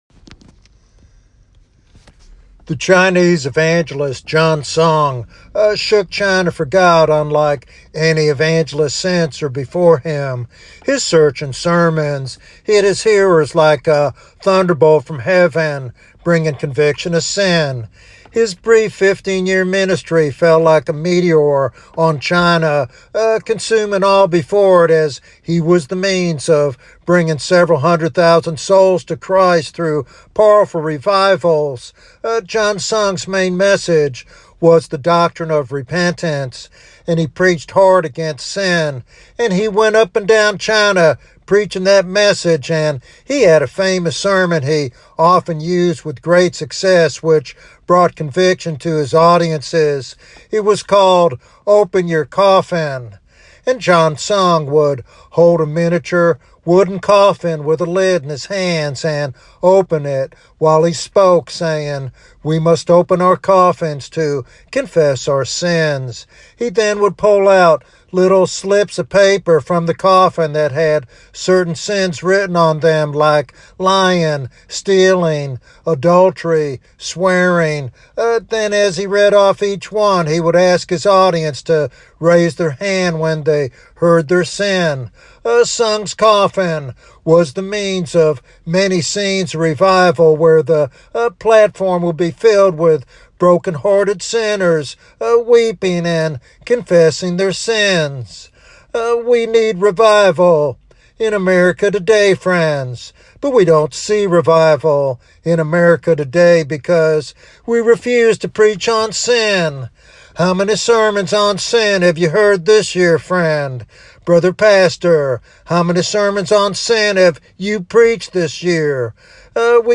In this stirring sermon